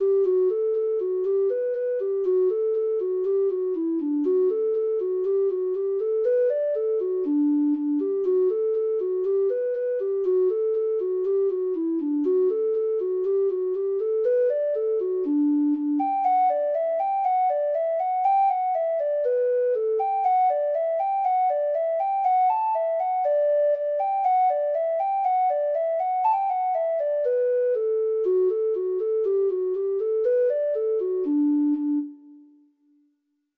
Free Sheet music for Treble Clef Instrument
Traditional Music of unknown author.
Reels
Irish